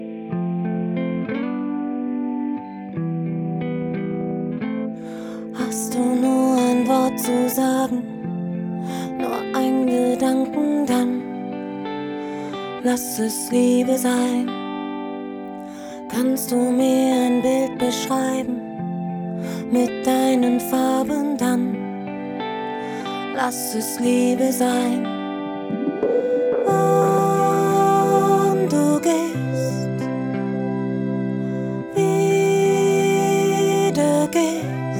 Жанр: Поп музыка / Электроника / Классика